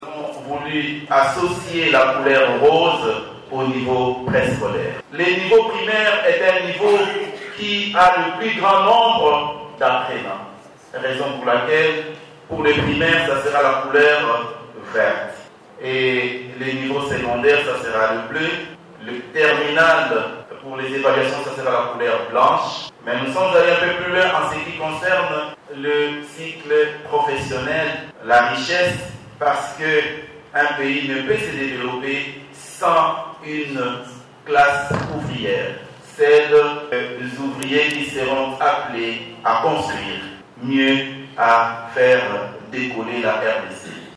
L’inspecteur général de l’Education nationale explique la qualité de ces documents scolaires importants à travers leurs couleurs respectives.